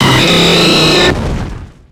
Cri de Braségali dans Pokémon X et Y.